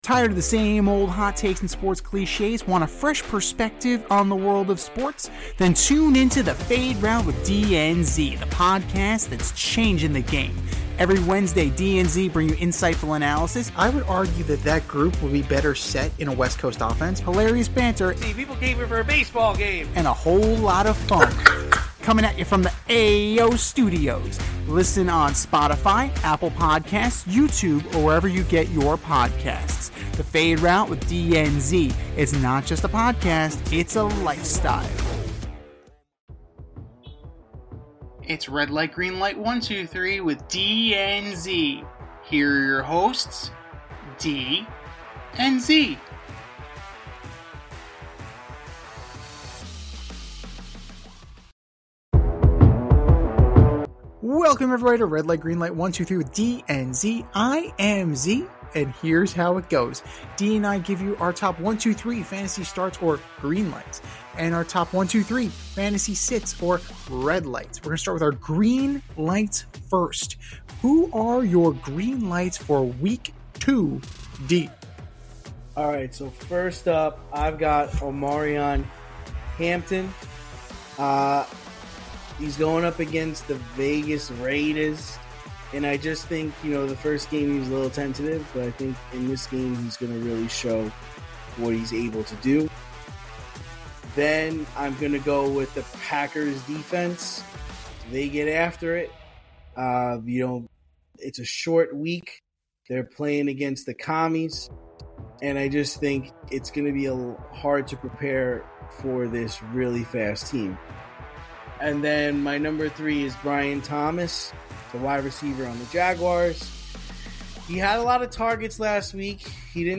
two veteran sports aficionados and lifelong friends, as they dissect the week’s top stories with wit and a touch of New York flair.